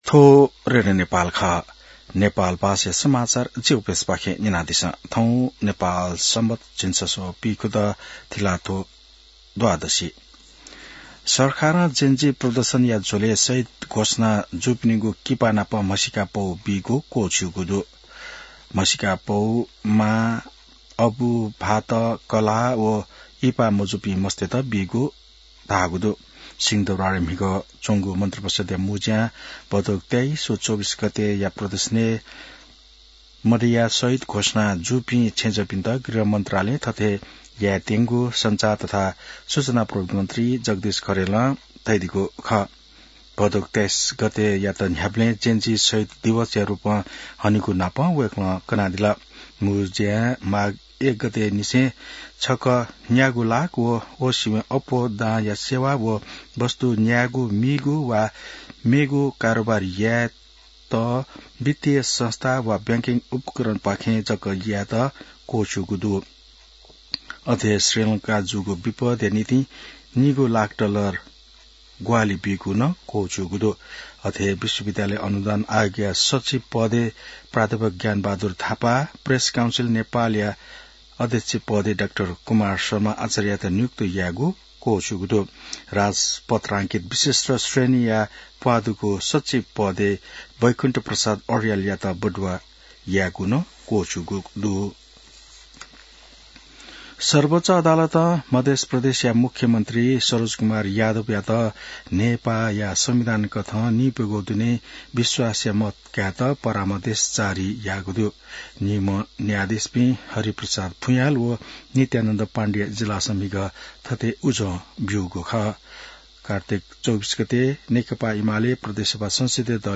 नेपाल भाषामा समाचार : १६ मंसिर , २०८२